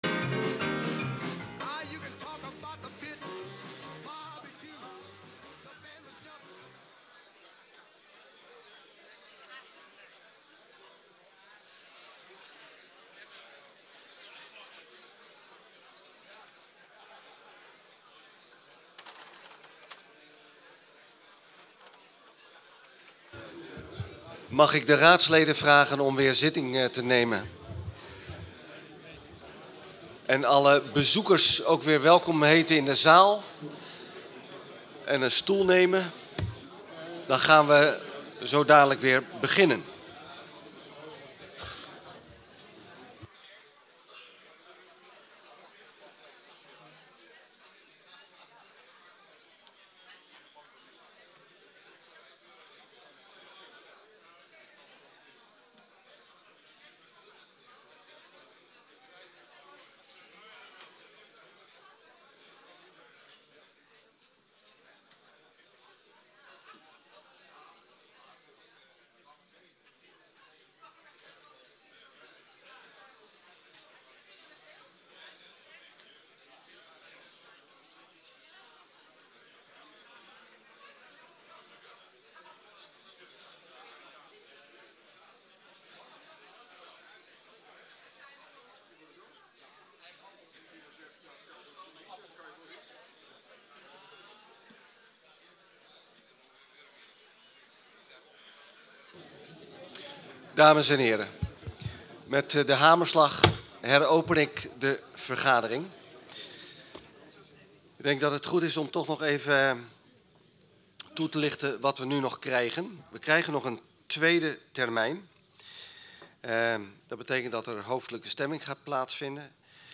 Gemeenteraad 28 juni 2016 20:00:00, Gemeente Haarlemmermliede
ONDERWERP Besluit over keuze fusie-partner. PLAATS: De Zoete Inval, Haarlemmerstraatweg 183, 2065 AE Haarlemmerliede Deze vergadering is geheel gewijd aan dit onderwerp.